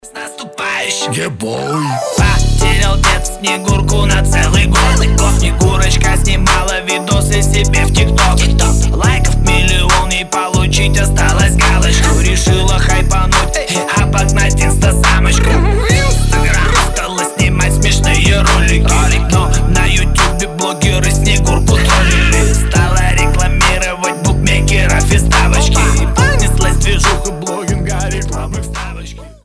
• Качество: 320, Stereo
веселые
прикольные